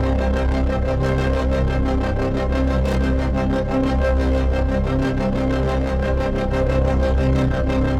Index of /musicradar/dystopian-drone-samples/Tempo Loops/90bpm
DD_TempoDroneA_90-C.wav